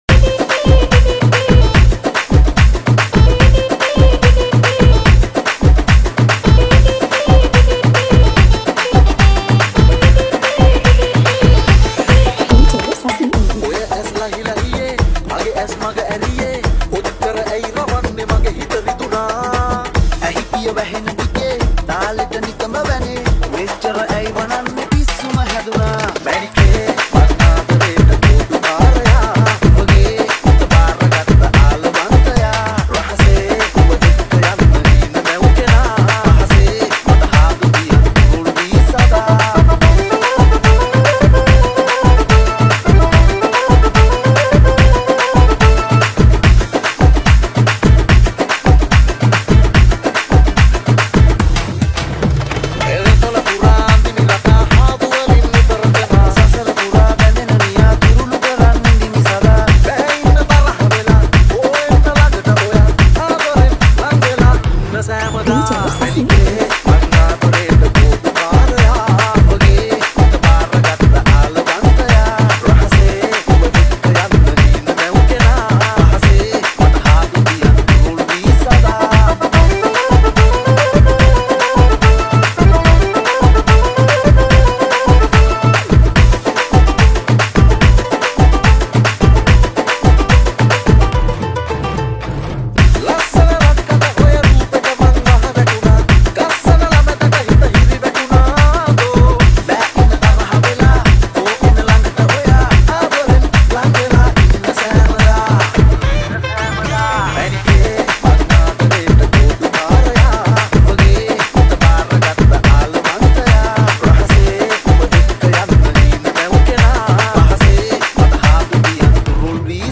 Releted Files Of Sinhala New Dj Remix Single Mp3 Songs